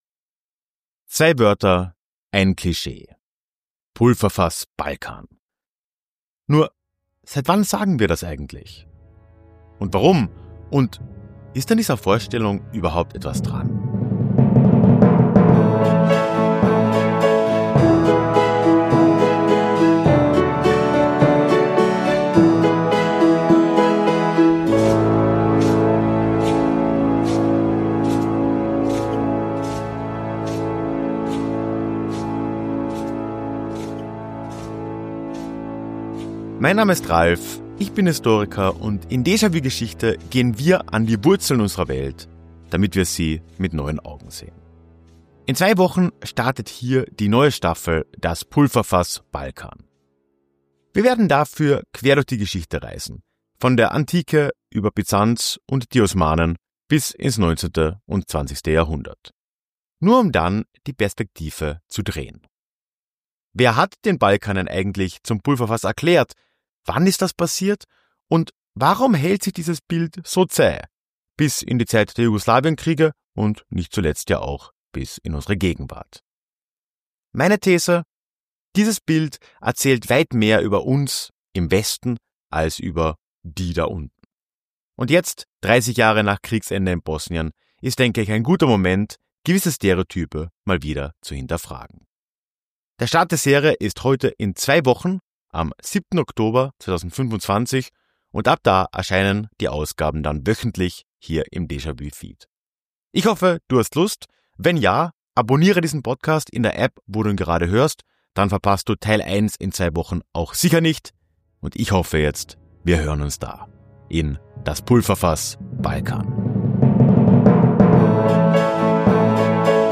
Die Deja-vu Jingle basiert auf einem Song meiner Band ERNST